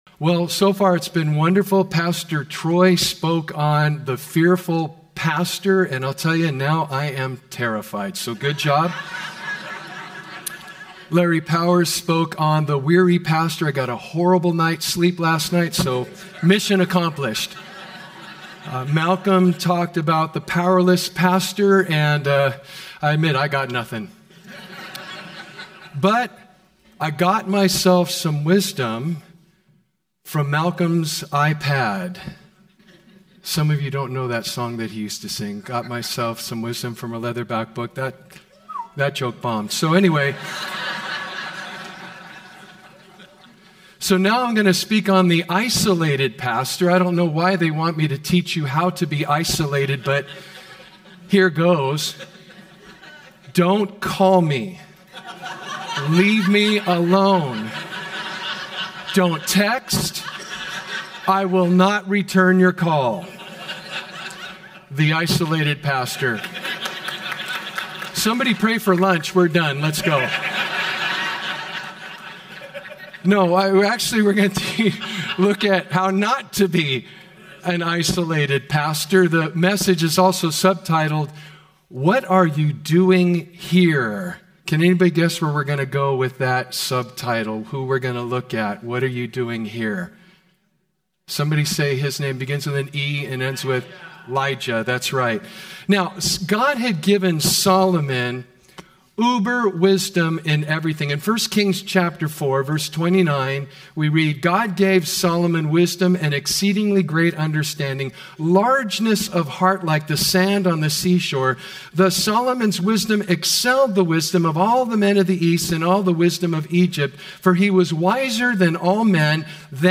Home » Sermons » “The Isolated Pastor”